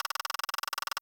rolling.wav